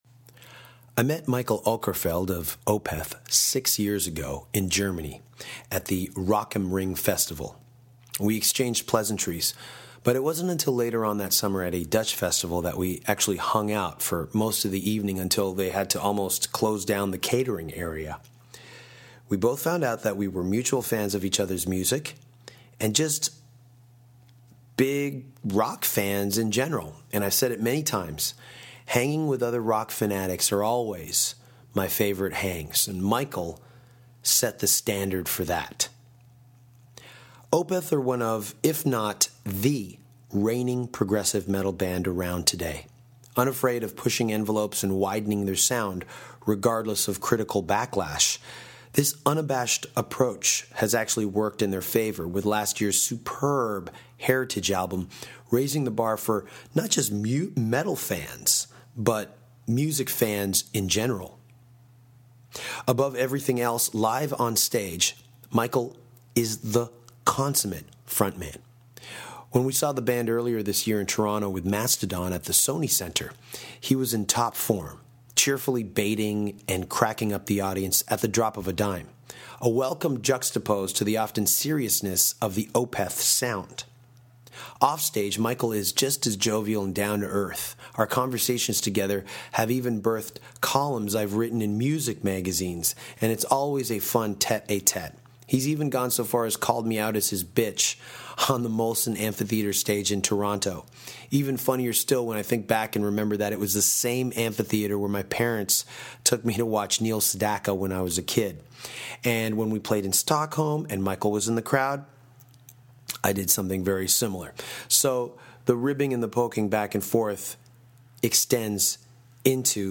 Danko caught up with Opeth leader, Mikael Åkerfeldt, this past August at The Wacken Open Air Festival in Germany and talked about singing techniques, soft music and Kiss, a lot of Kiss.